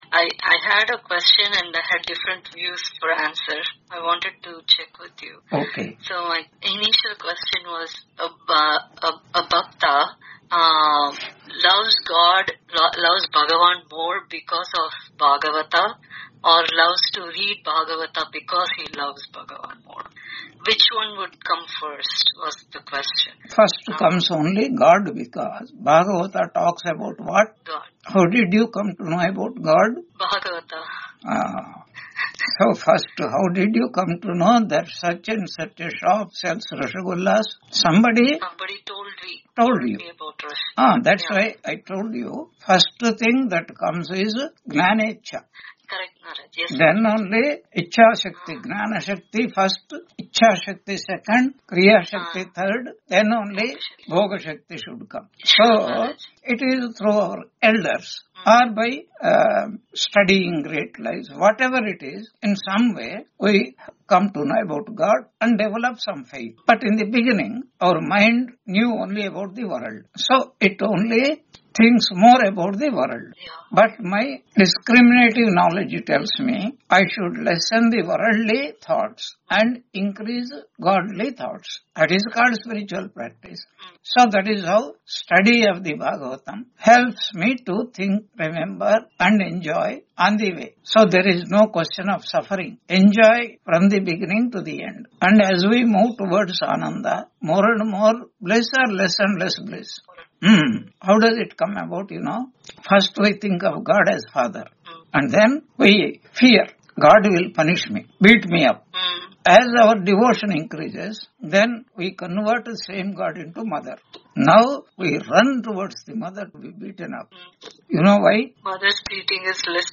Taittiriya Upanishad Lecture 83 Ch2 6.3 on 17 December 2025 Q&A - Wiki Vedanta